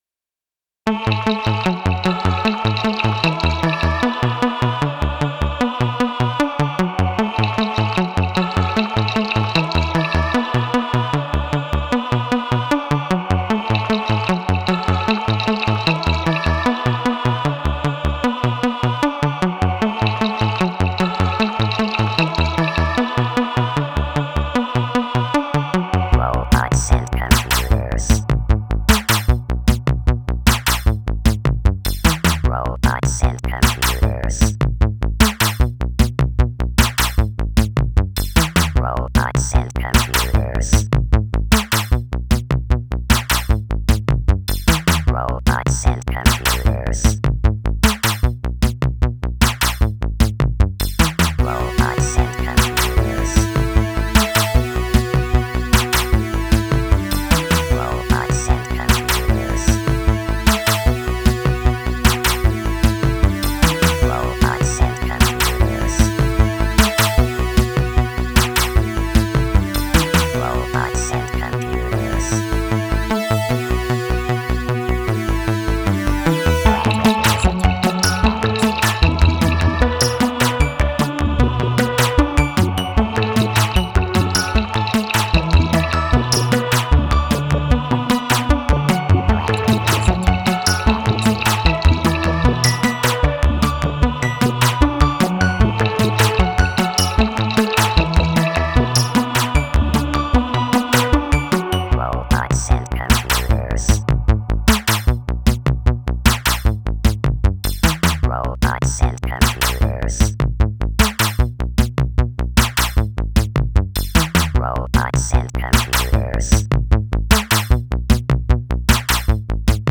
Genre: IDM, Minimal Wave, Synth-pop.